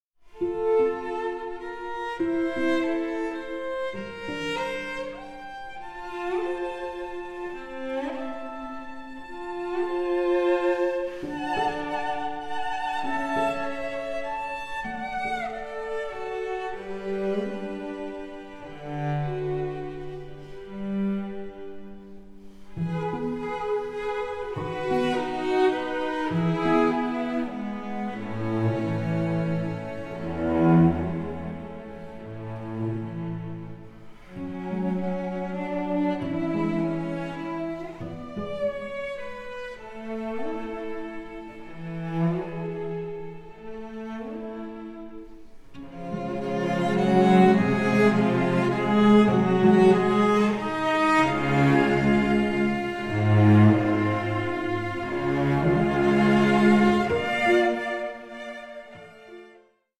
HAUNTING, GENTLE SPIRITS, DREAMS, AND LULLABIES
all above a rich bed of cellos